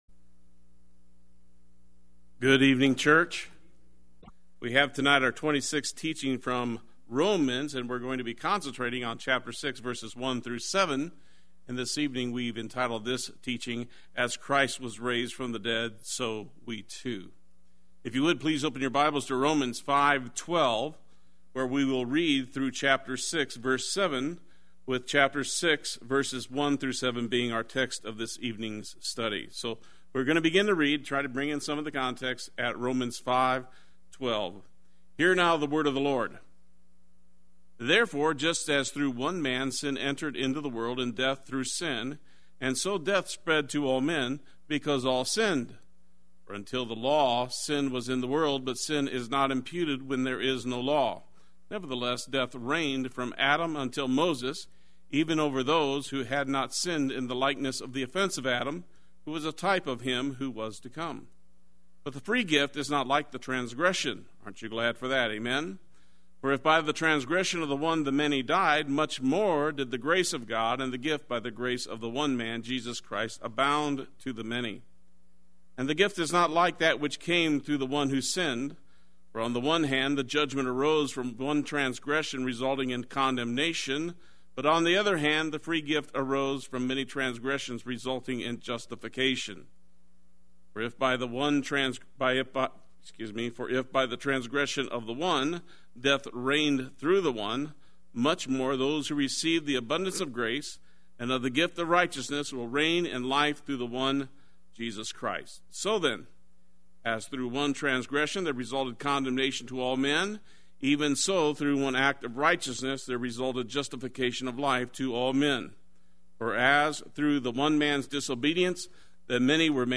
Play Sermon Get HCF Teaching Automatically.
So We Too Wednesday Worship